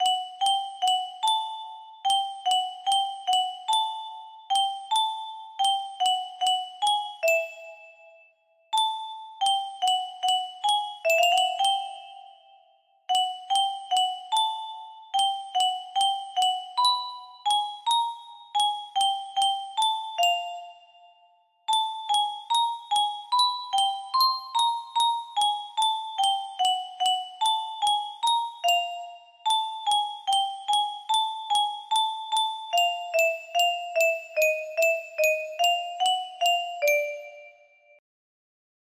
Schubert - Serenade music box melody